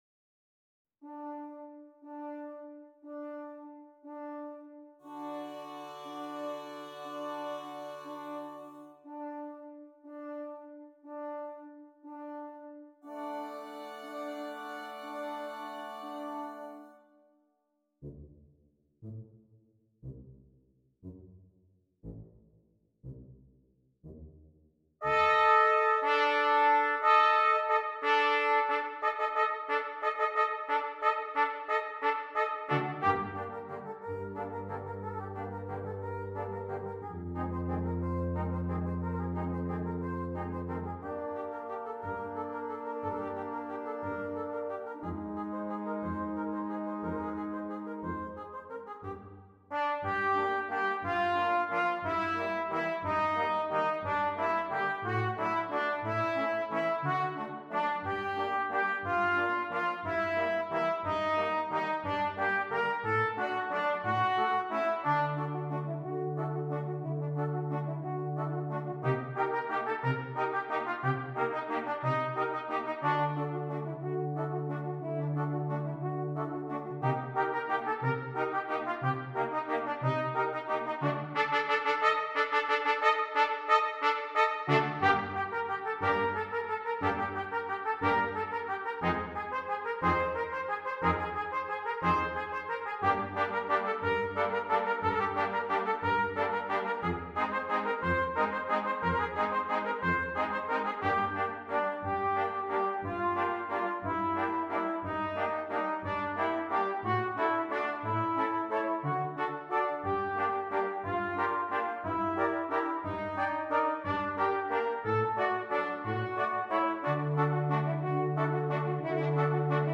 Brass Quintet
great use of melody and dissonance
and now a classic for quintet.